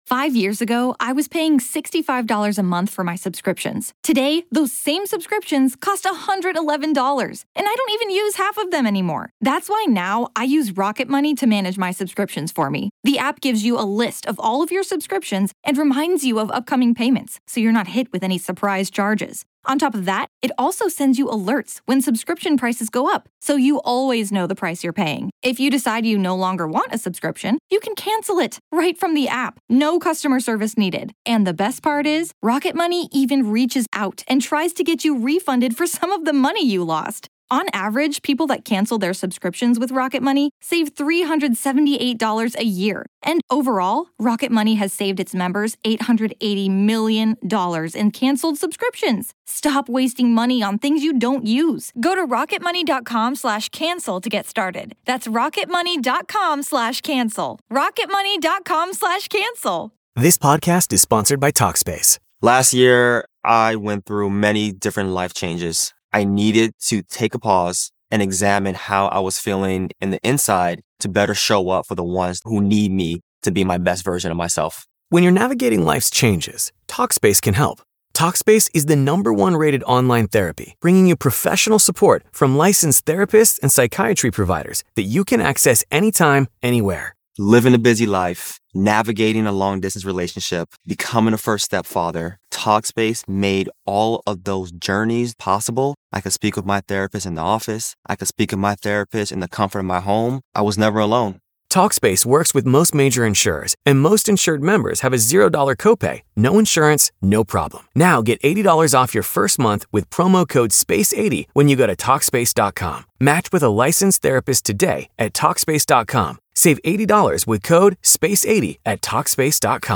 Kunitsu-Gami: Path of the Goddess - A Conversation with the Development Team